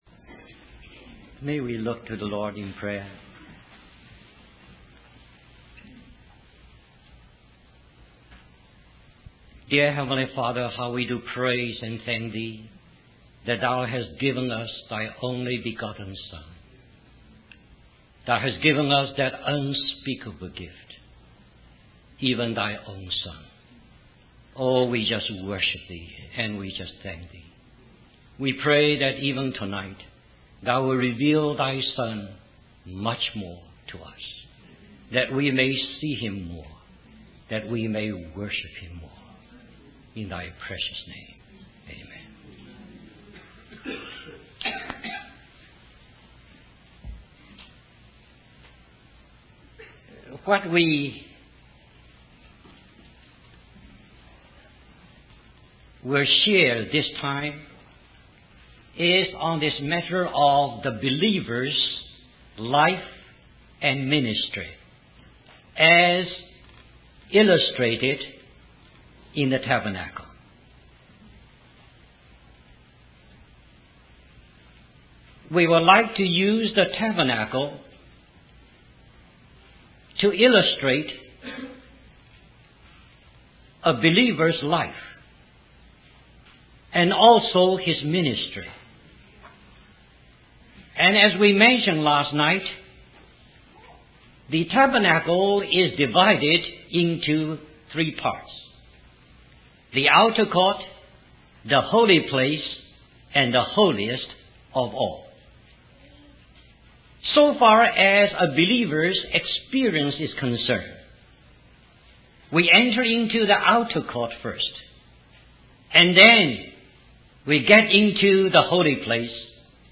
In this sermon, the speaker emphasizes the importance of the cross in our approach to God.